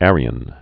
(ărē-ən, âr-)